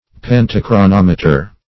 Search Result for " pantochronometer" : The Collaborative International Dictionary of English v.0.48: Pantochronometer \Pan`to*chro*nom"e*ter\, n. [Panto- + chronometer.] An instrument combining a compass, sundial, and universal time dial.